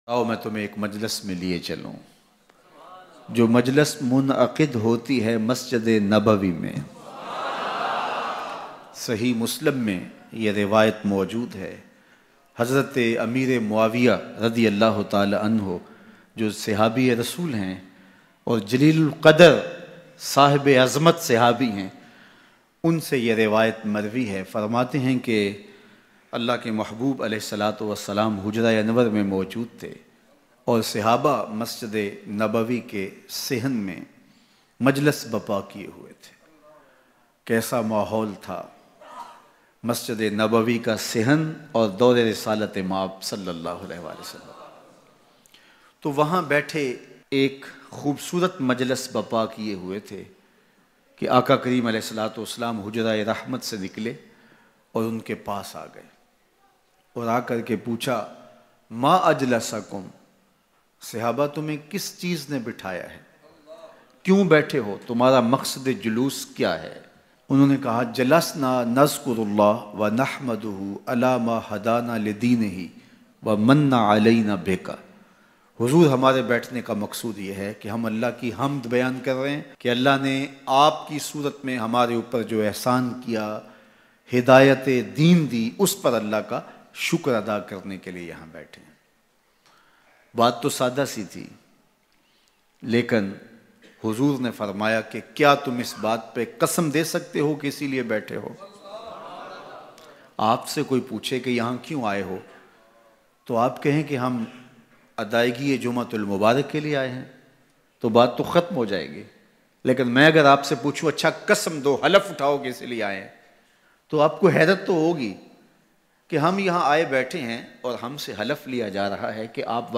Bayan